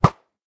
bow.ogg